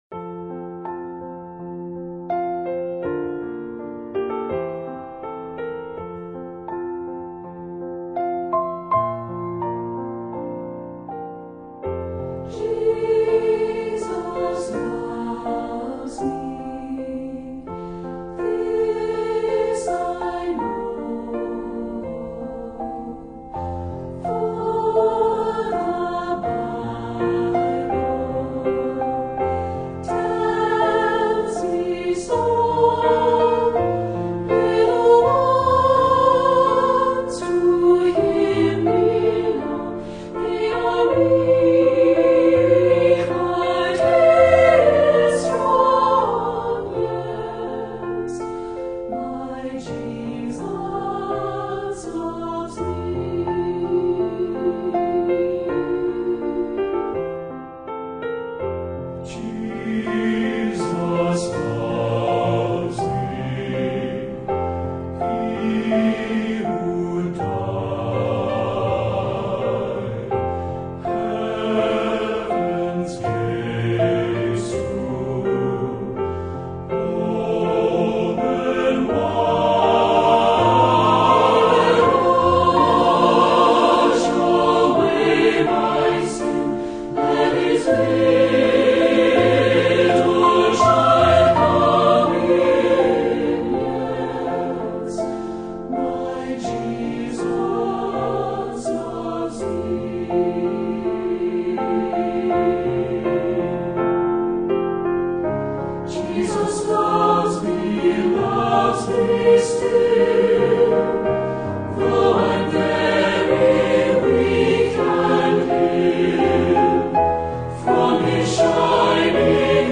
Voicing: SSAA and Piano